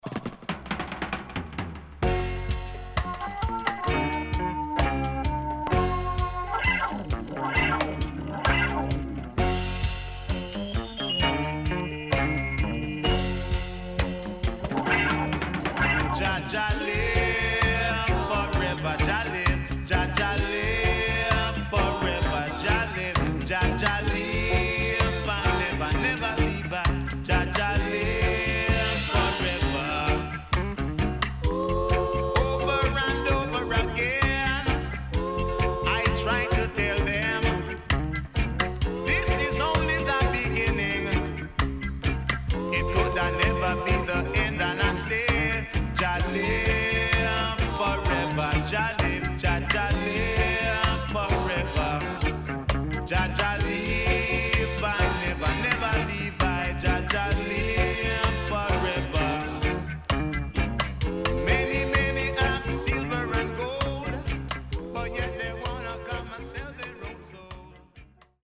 HOME > LP [DANCEHALL]
SIDE A:所々プチノイズ入ります。